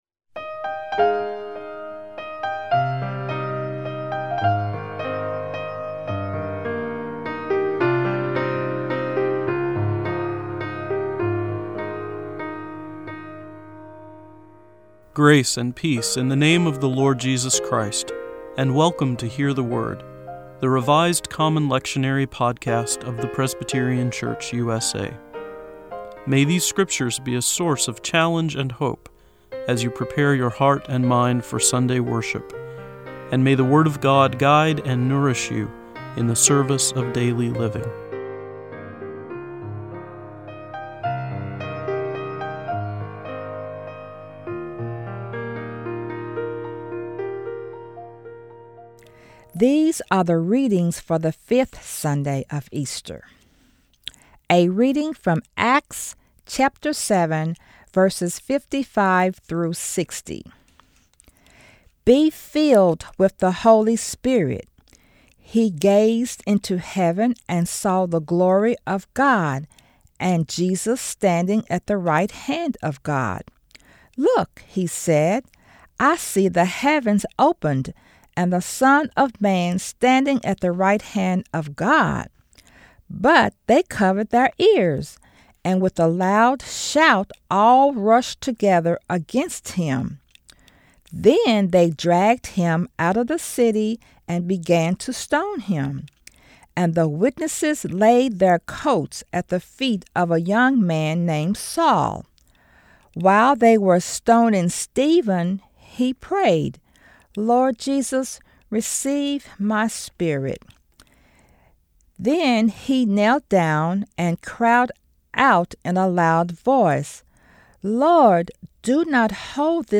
Each podcast (MP3 file) includes four lectionary readings for one of the Sundays or festivals of the church year: an Old Testament reading, a Psalm, an Epistle and a Gospel reading. Following each set of readings is a prayer for the day from the Book of Common Worship.